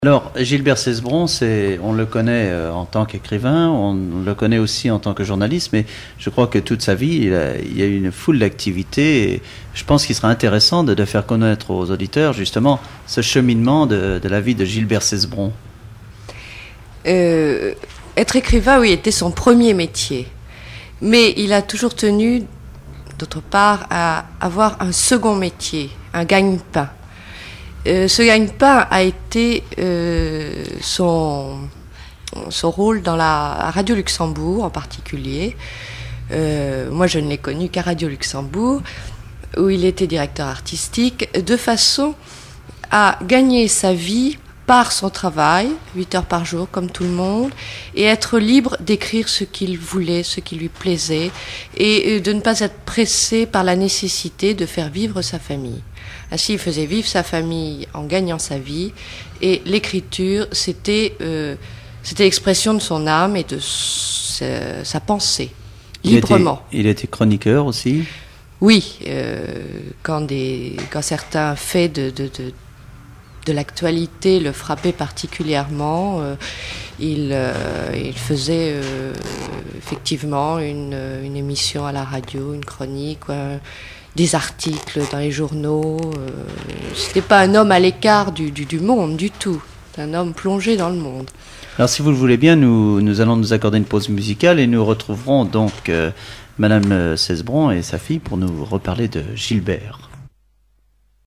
Enquête Alouette FM numérisation d'émissions par EthnoDoc
interview
Catégorie Témoignage